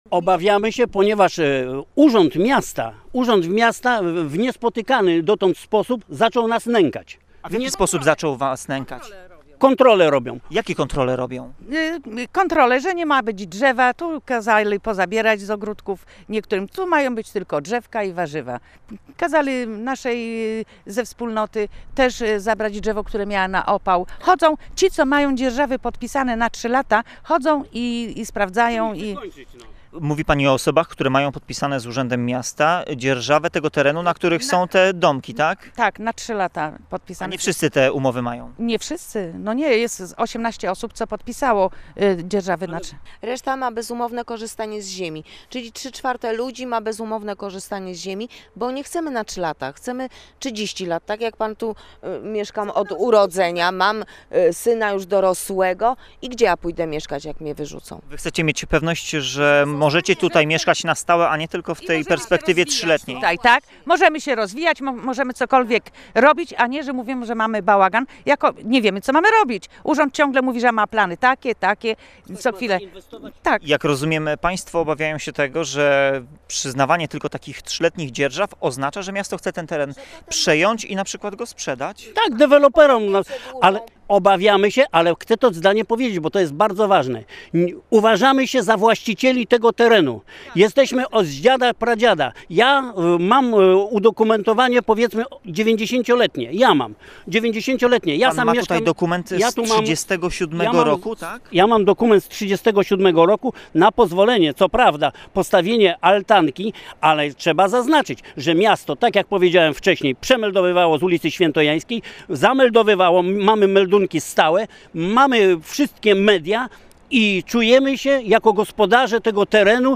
[REPORTAŻ]